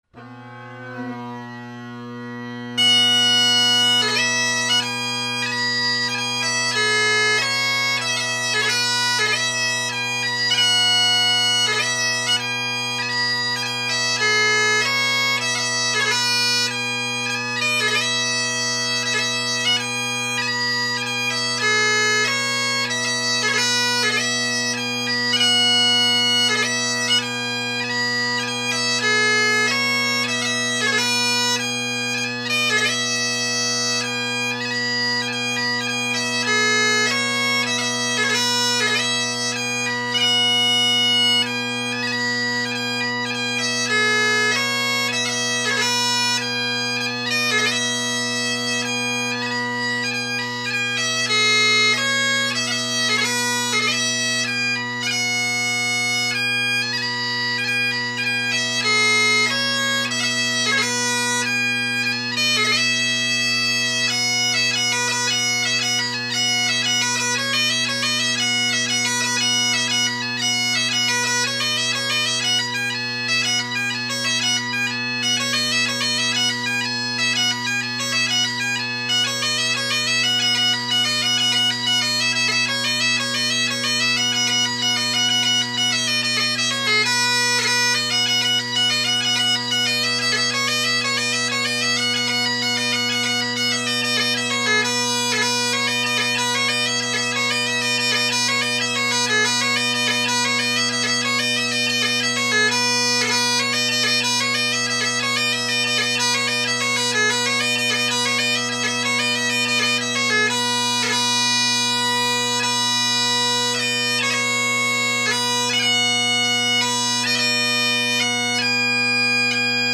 This chanter is louder than any other I’ve recorded, so pay attention to your speaker/headphone volume.
My Band’s New Medley – facing the mic
The pipes played are my Colin Kyo bagpipe with Ezee tenors and short inverted Ezee bass.